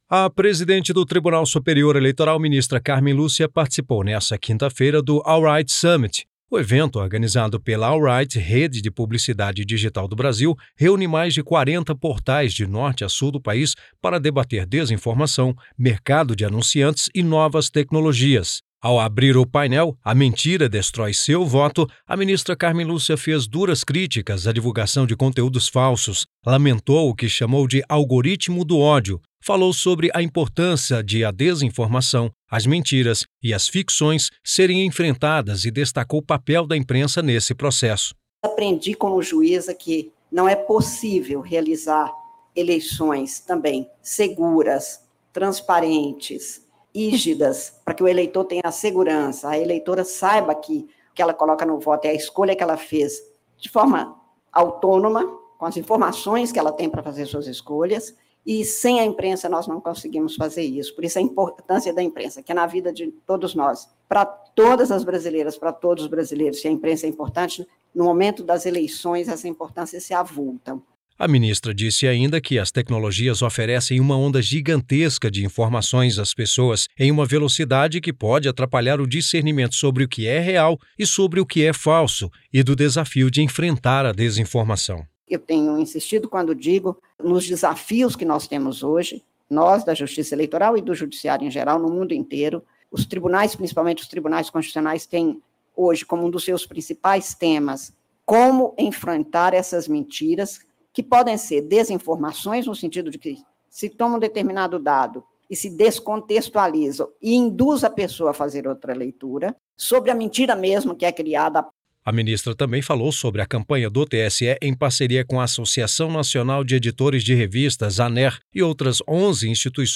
Em evento nessa quarta (21), em Brasília, ministra Cármen Lúcia falou sobre o tema “A mentira destrói seu voto”.